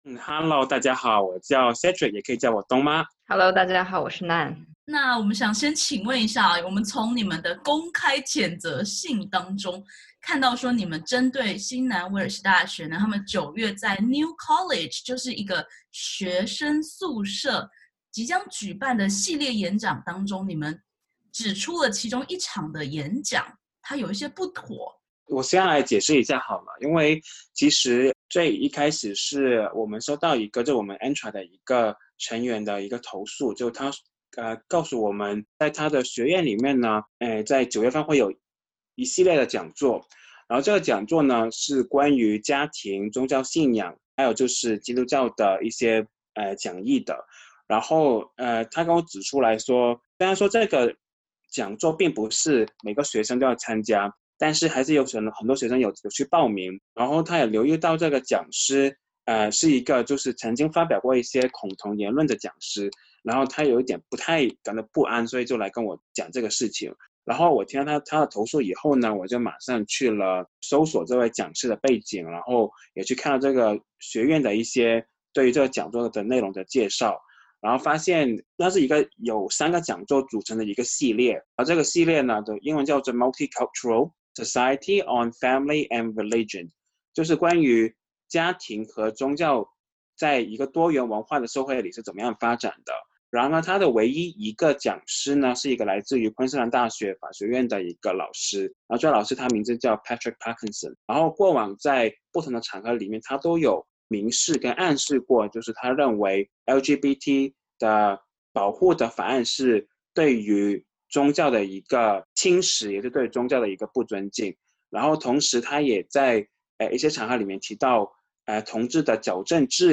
华人同志团体澳纽彩盟（ANTRA），日前公开谴责UNSW附属宿舍New College，即将在九月给本科生举办讲座内容不当，有宣导“恐同”思想的疑虑。点击图片收听完整采访音频。